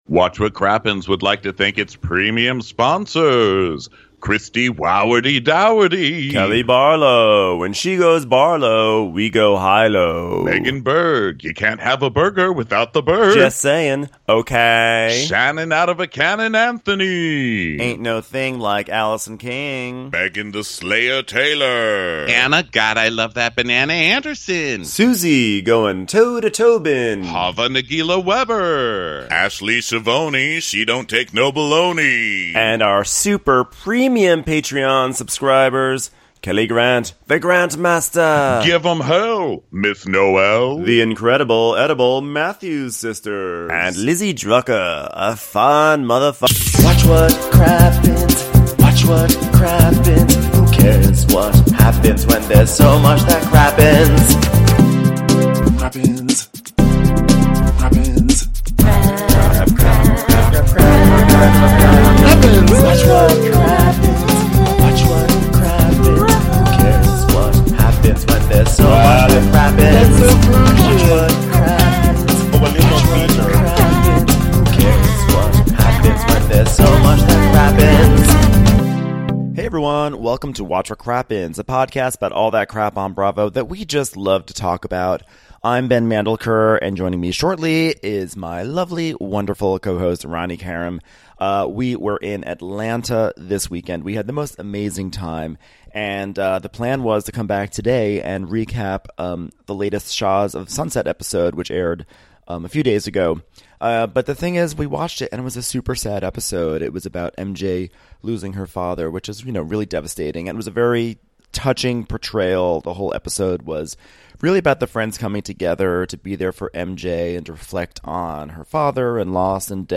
#779: Revisiting the RHOA Series Premiere - Live from Atlanta!